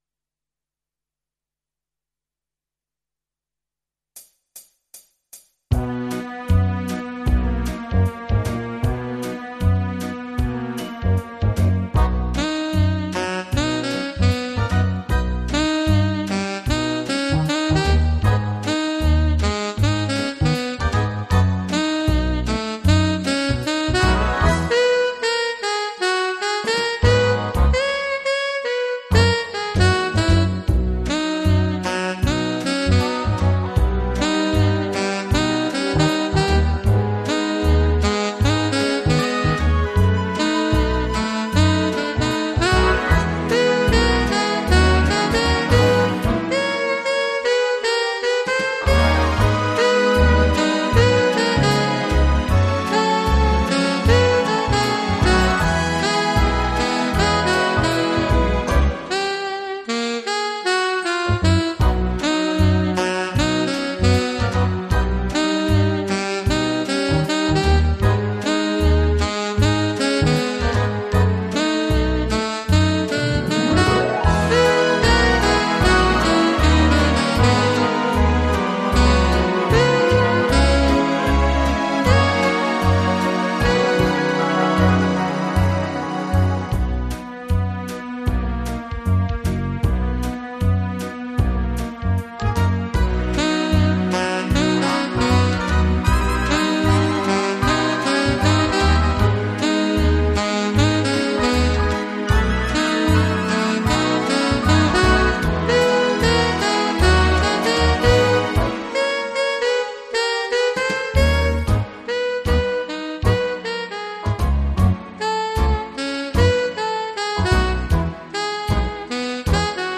version instrumentale multipistes